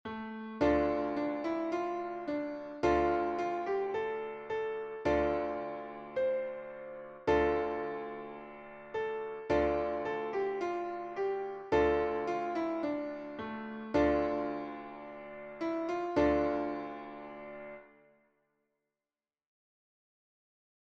is a traditional Hebrew folk song.